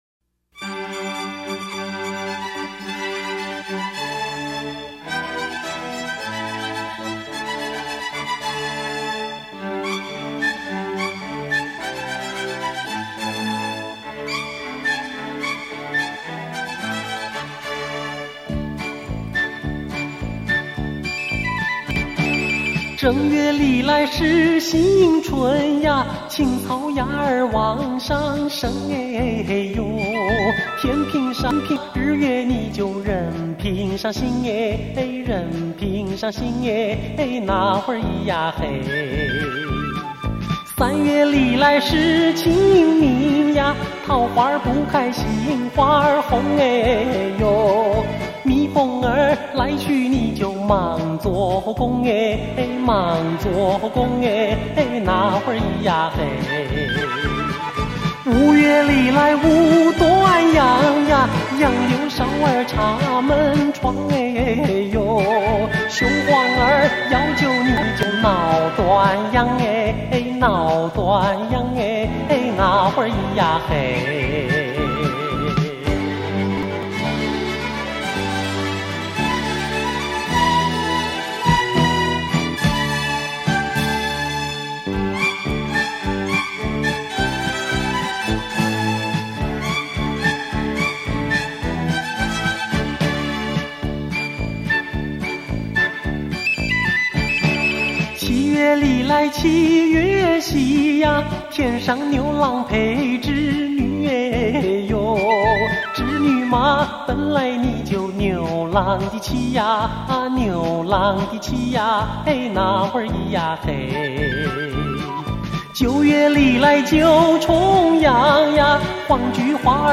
甘肃民谣